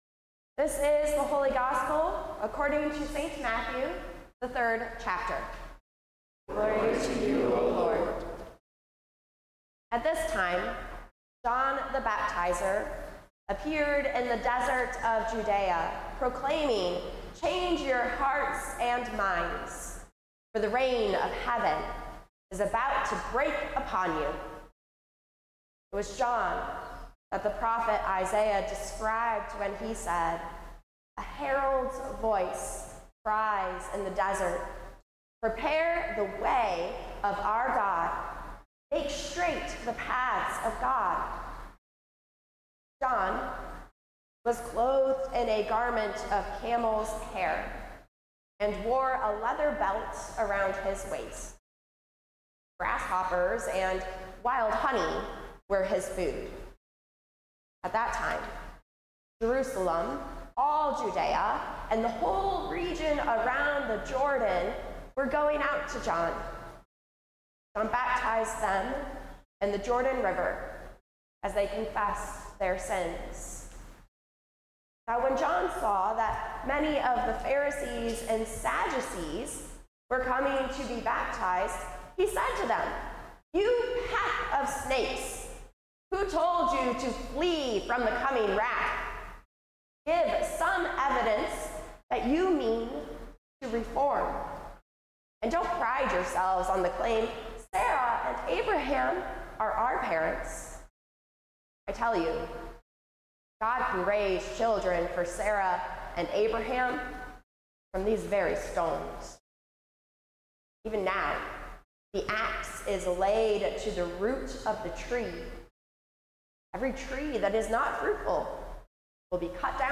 Sermons | Grace Evangelical Lutheran Church